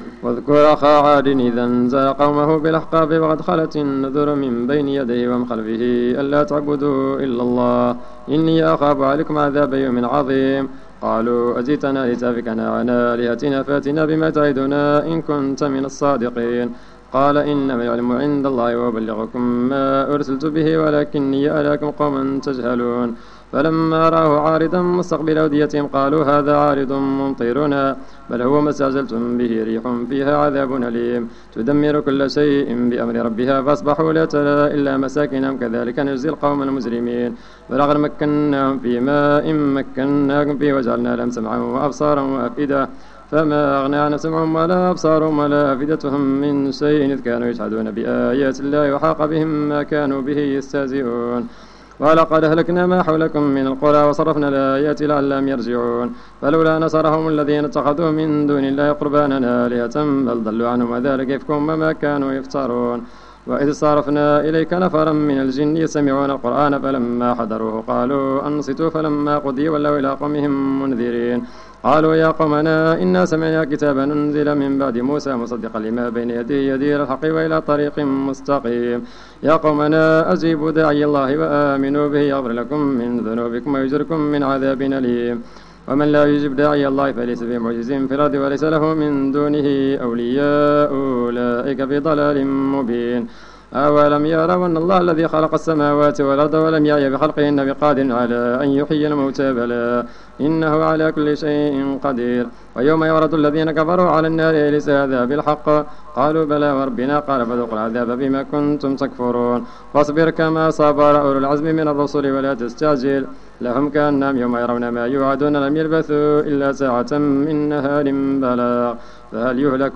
صلاة التراويح ليوم 25 رمضان 1431 بمسجد ابي بكر الصديق ف الزو
صلاة رقم 01 ليوم 25 رمضان 1431 الموافق سبتمبر 2010